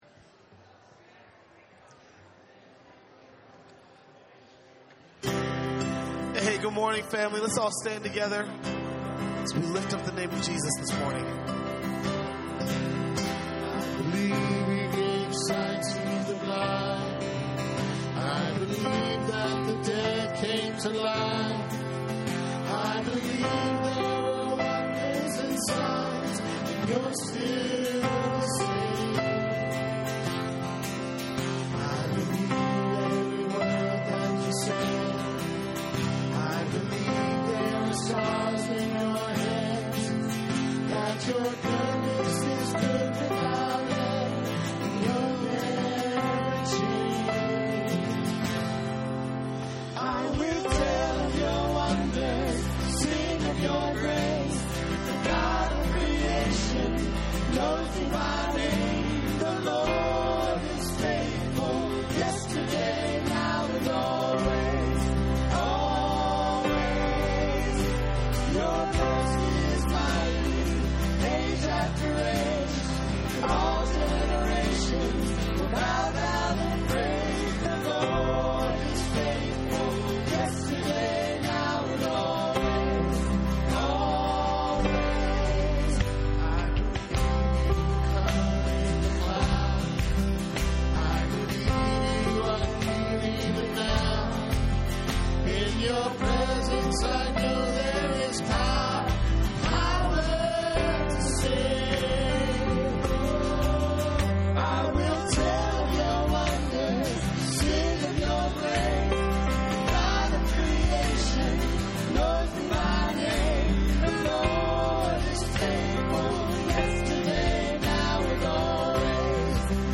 " Ephesians " Sermon Notes